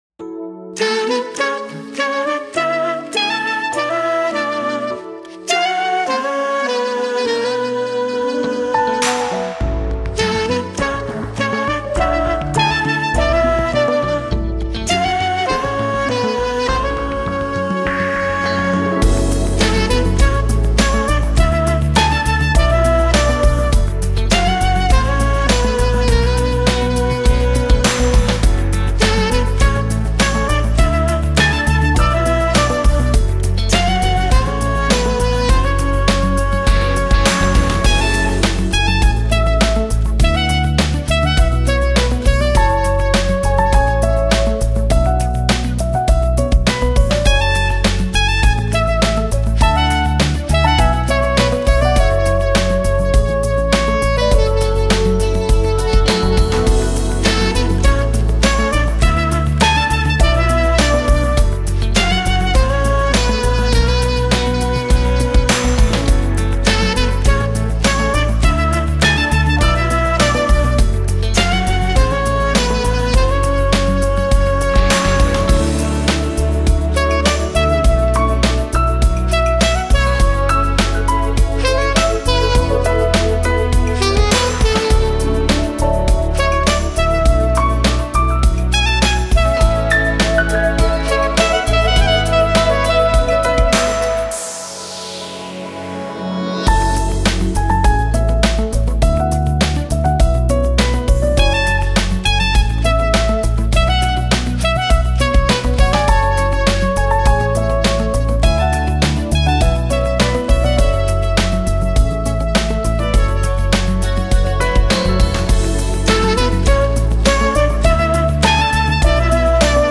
Genre: Jazz, Smooth Jazz
音乐风格：Contemporary Jazz,Crossover Jazz,Smooth Jazz